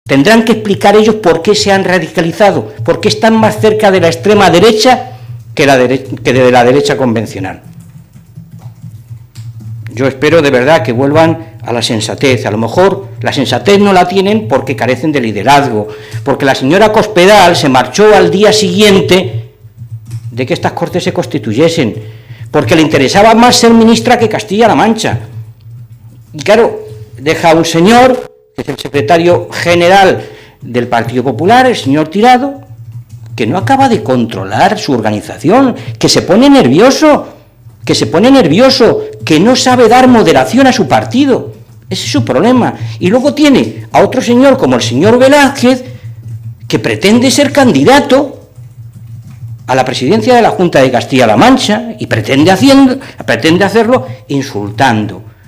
Así lo ha expresado en una rueda de prensa el diputado regional de los socialistas en las Cortes de CLM, Fernando Mora.